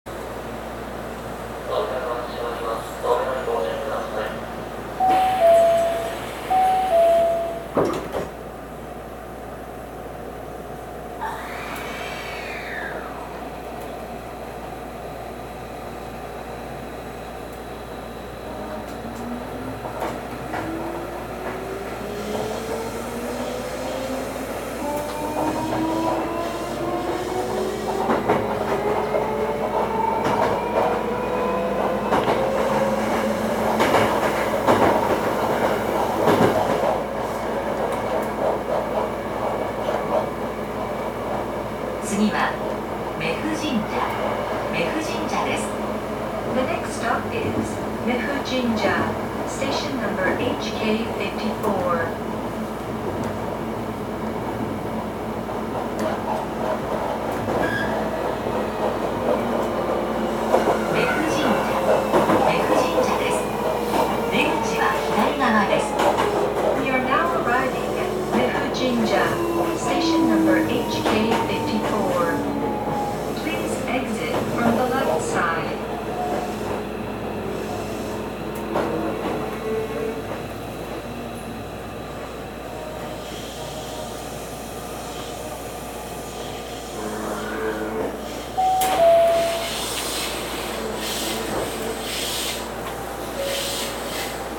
走行機器は定格190kWの全閉自冷式永久磁石同期電動機を採用しており、消費電力と騒音の低減を図っています。
走行音
録音区間：中山観音～売布神社(急行)(お持ち帰り)